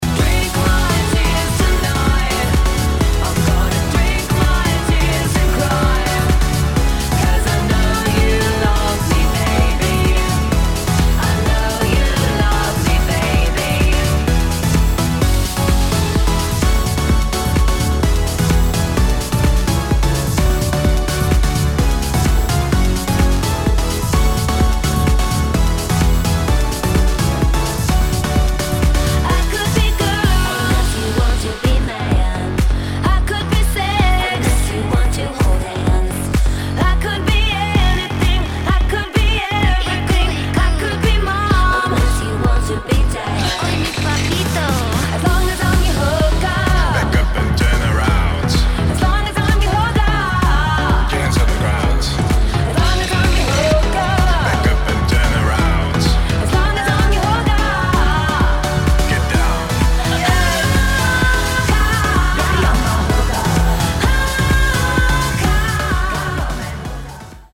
[ POP | HOUSE | DISCO ]